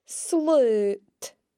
The broad L sound can sound a bit different when combined with another consonant at the beginning of a word, such as in sload (pull) or cluich (play):